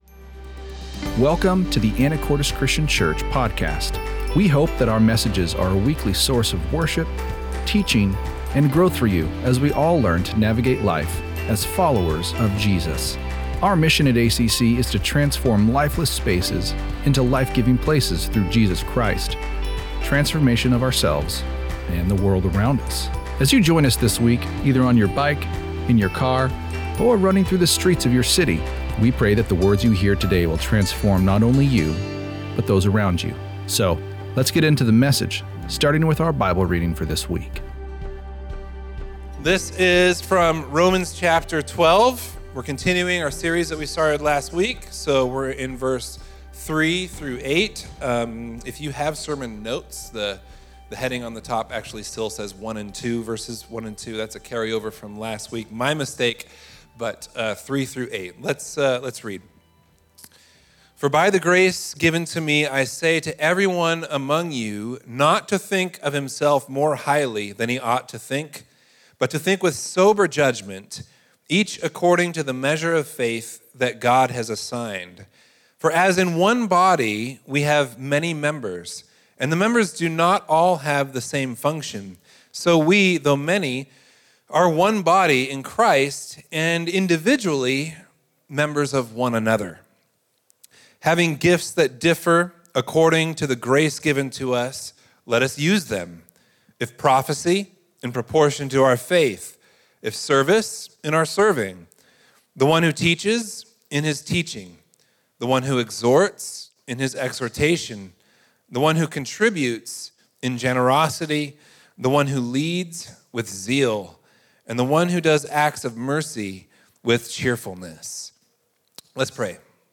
The sermon emphasized that the church’s mission involves both evangelism and discipleship.